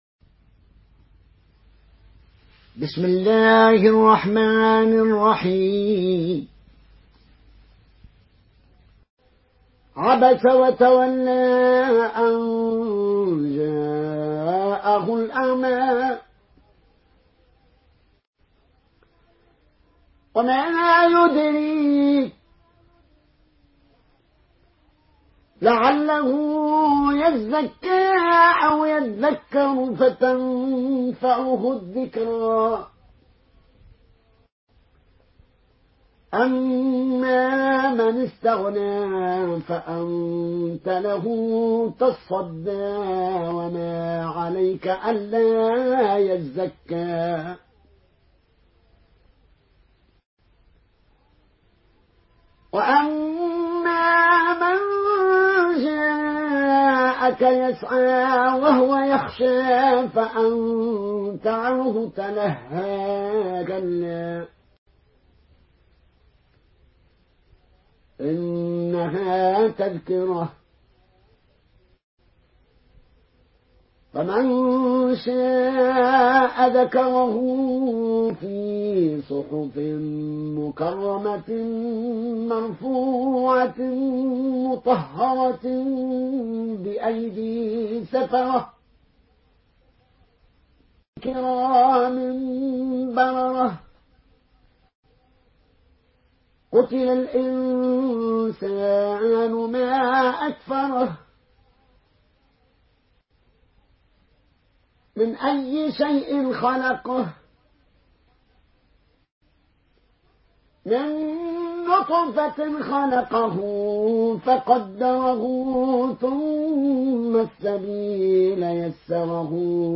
Une récitation touchante et belle des versets coraniques par la narration Qaloon An Nafi.